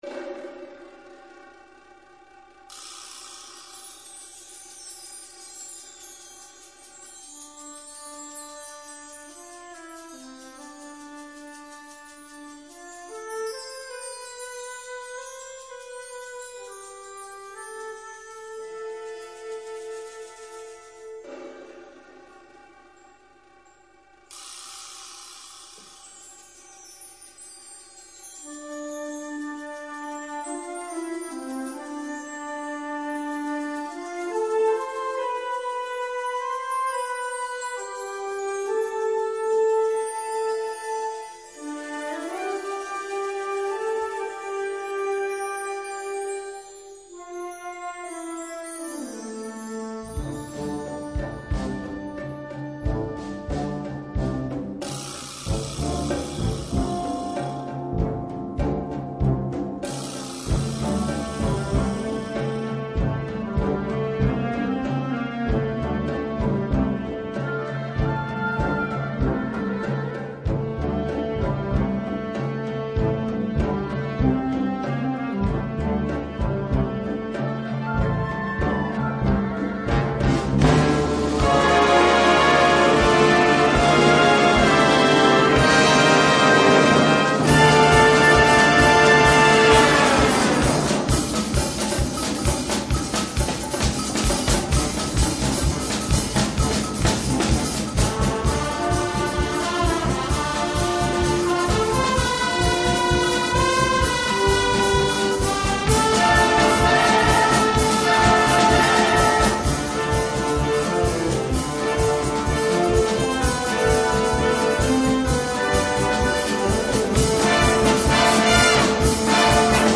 Gattung: Fantasie
23 x 30,5 cm Besetzung: Blasorchester Zu hören auf